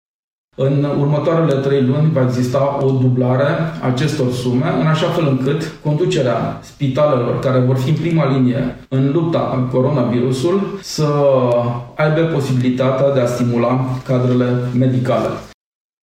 Șeful administrației județene a mai anunțat că la prima rectificară bugetară de săptămâna viitoare, din bugetul județului va fi alocată suma de 60.000 de lei pentru acordarea de stimulente medicilor brașoveni care sunt în prima linie în lupta cu coronavirusul: